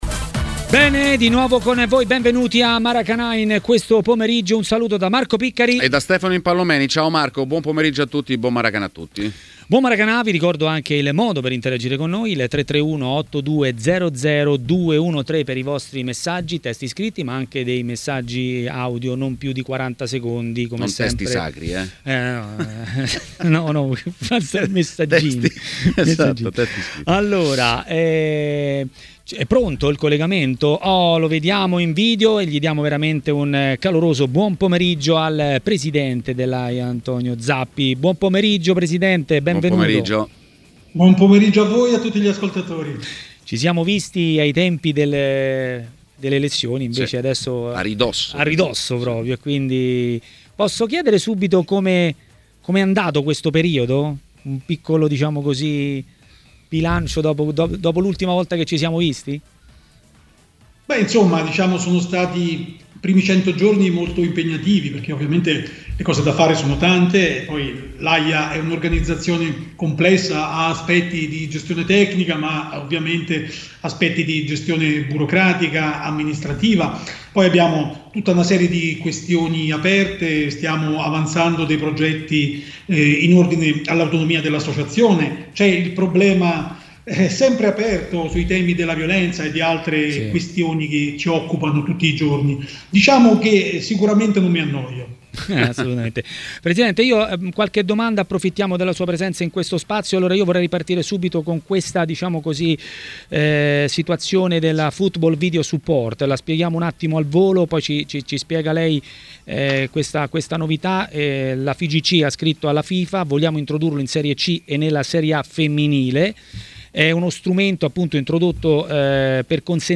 A intervenire in diretta a TMW Radio, durante Maracanà, è stato Antonio Zappi, nuovo presidente dell'Associazione Italiana Arbitri (AIA).